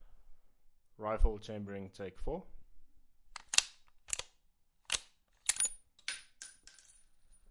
描述：拧上.308步枪的抑制器